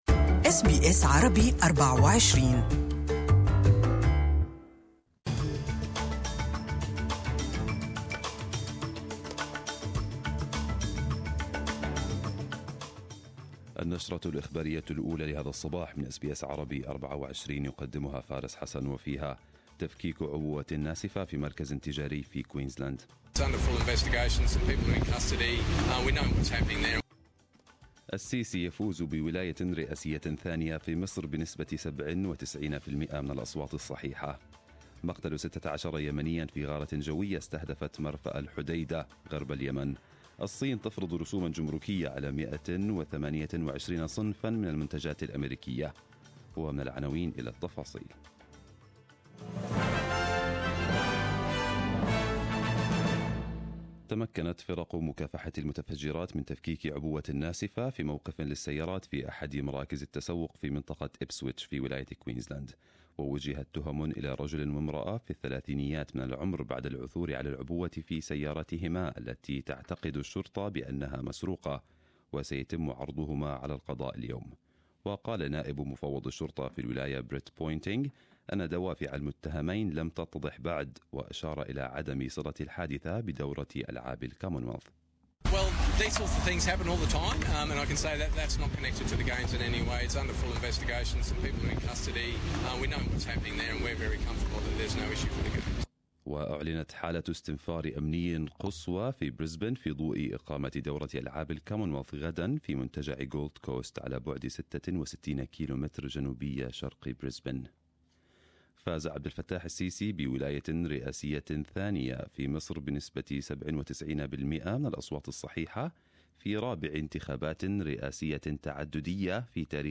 Arabic News Bulletin 03/04/2018